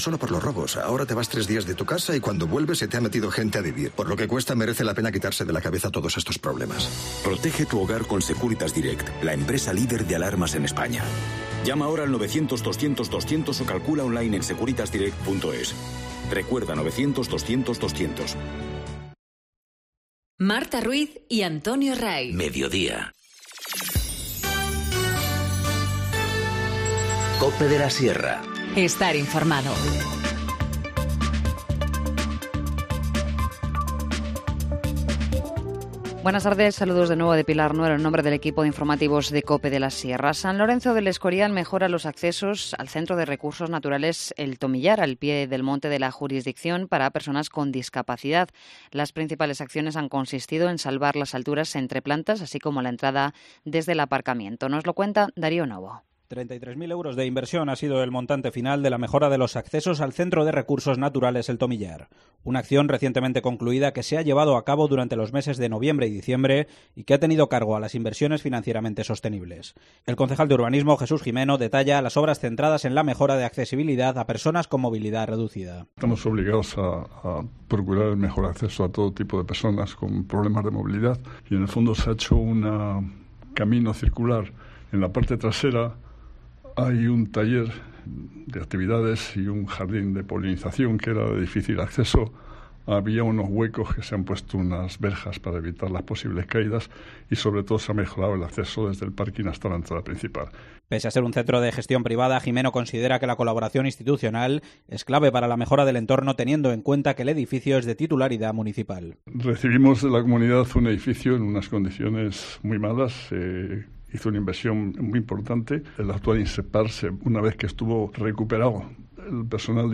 Informativo Mediodía 22 enero- 14:50h